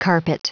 Prononciation du mot carpet en anglais (fichier audio)